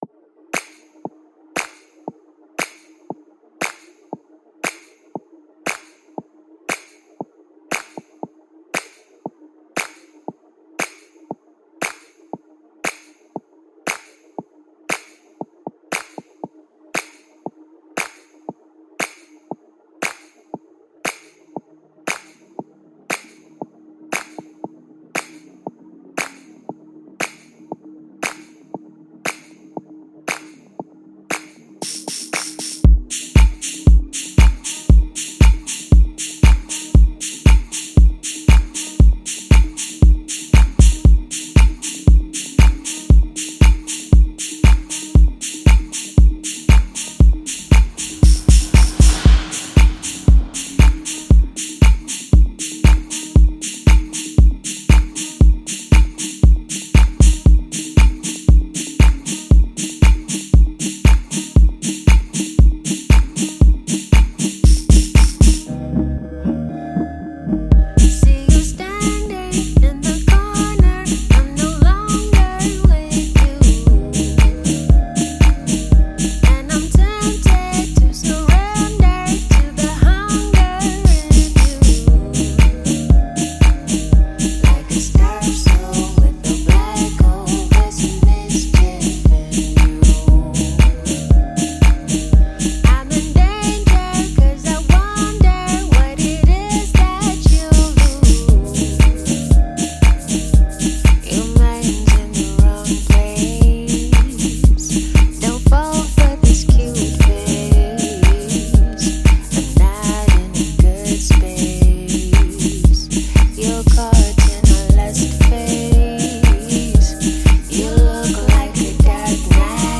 deep house song